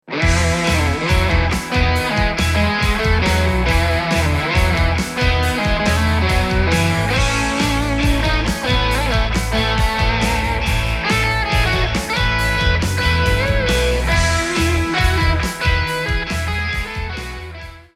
rock instrumental à la guitare
Guitare électrique
Batterie
Basse